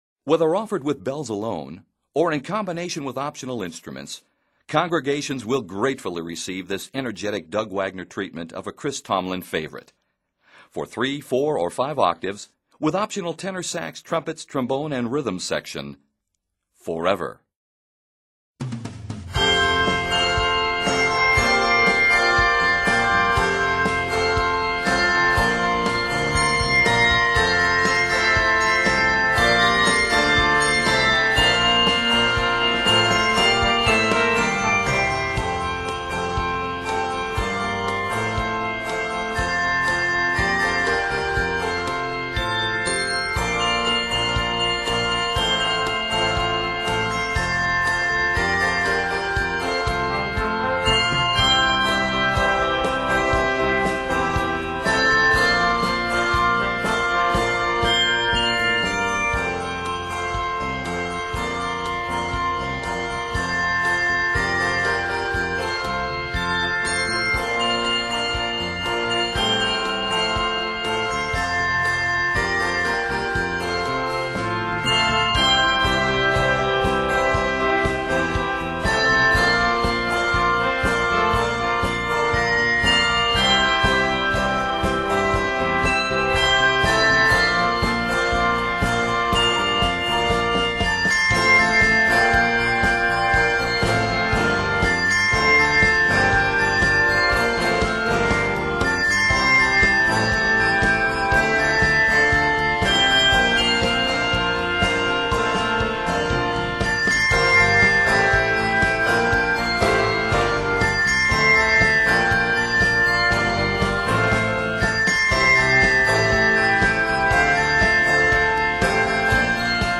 energetic treatment
It is set in F Major.